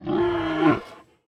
Minecraft Version Minecraft Version 1.21.5 Latest Release | Latest Snapshot 1.21.5 / assets / minecraft / sounds / mob / panda / aggressive / aggressive3.ogg Compare With Compare With Latest Release | Latest Snapshot
aggressive3.ogg